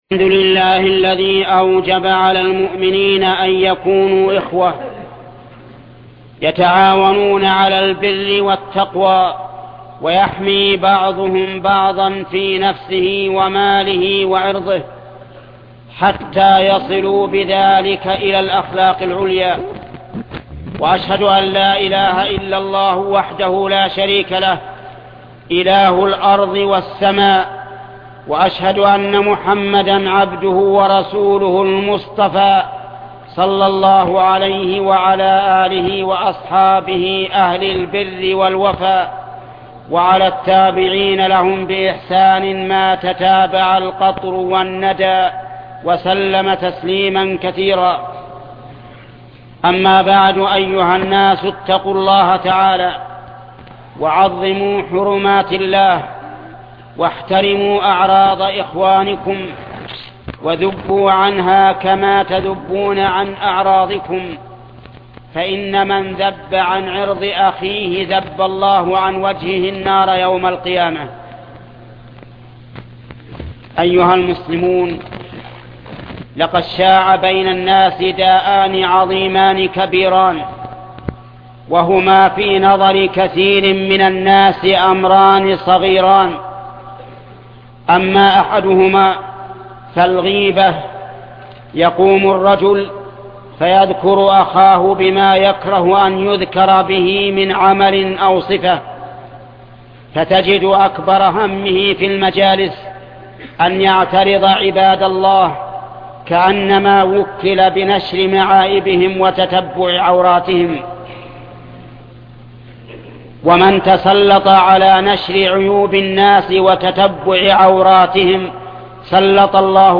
خطبة تحريم الغيبة والنميمة ـ تحريم التحايل على الأمور المحرمة والالتزام بأمر الله ورسوله الشيخ محمد بن صالح العثيمين